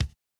MOO Kick 2.wav